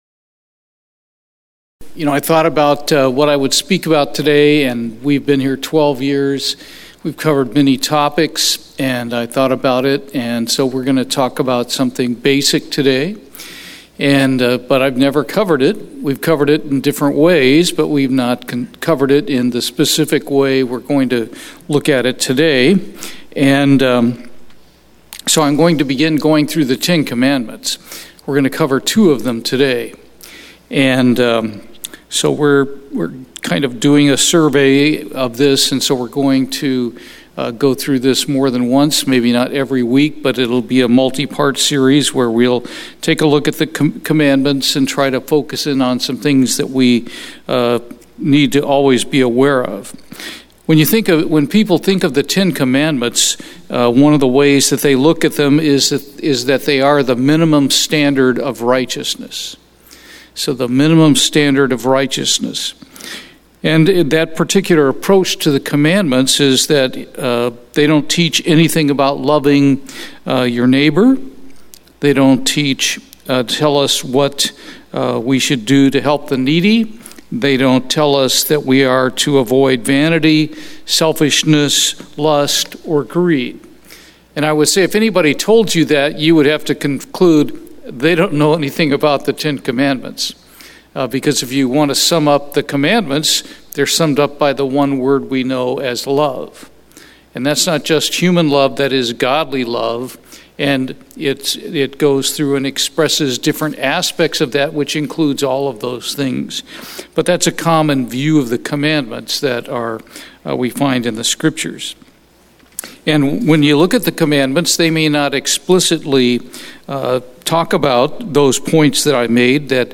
This is the first in a series of sermons on the Ten Commandments.
Given in Houston, TX